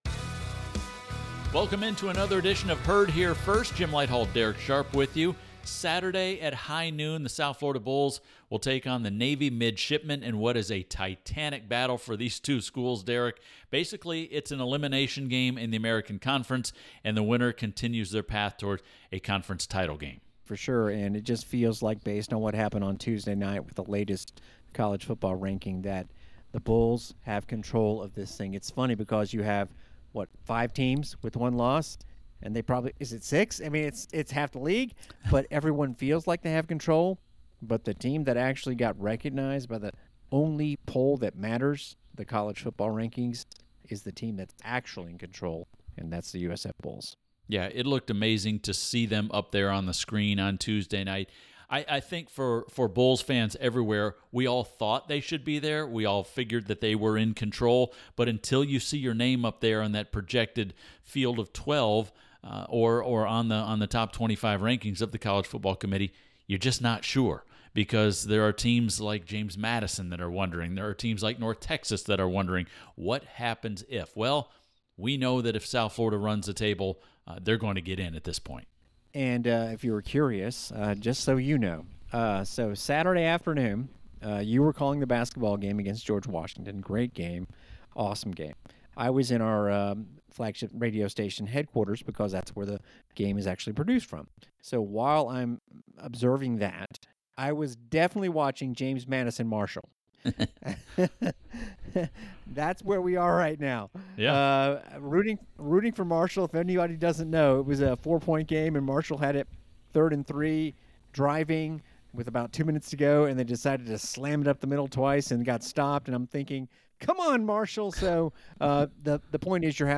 Herd Here First: Hour 1 Radio Pregame Show USF at Navy